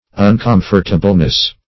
[1913 Webster] -- Un*com"fort*a*ble*ness, n. --